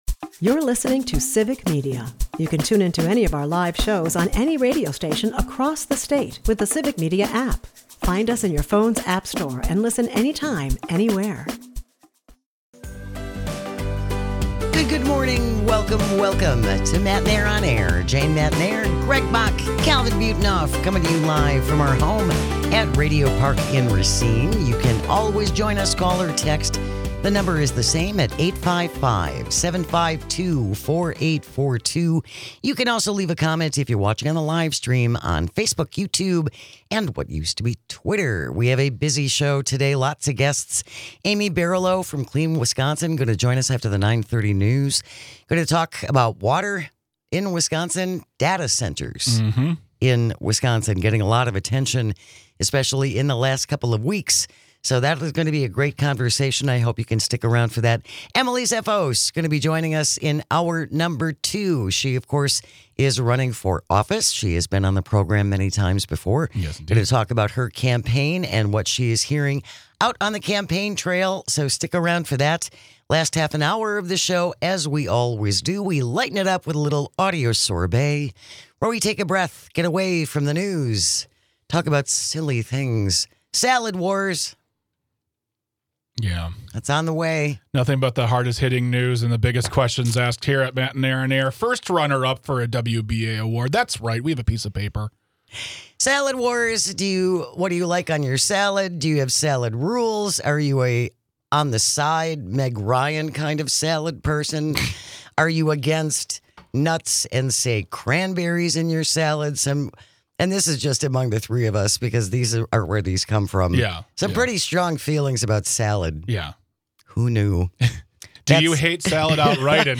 Matenaer On Air is a part of the Civic Media radio network and airs weekday mornings from 9-11 across the state.